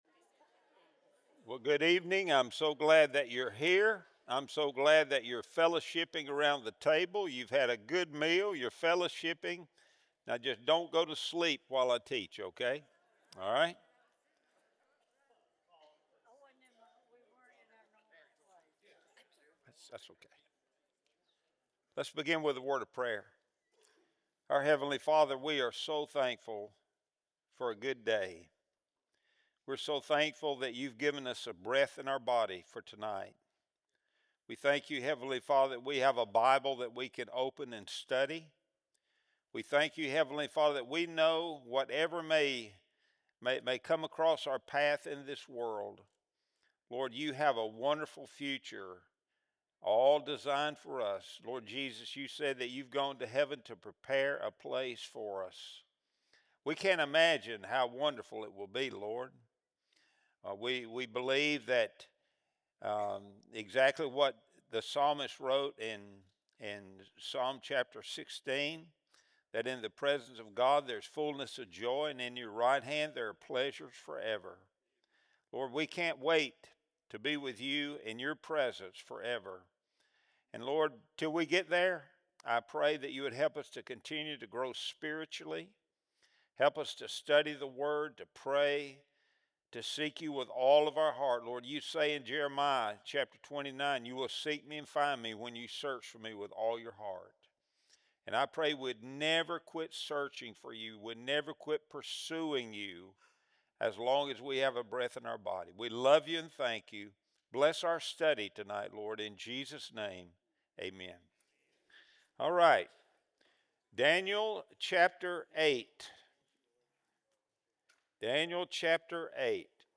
Wednesday Bible Study Series | November 19, 2025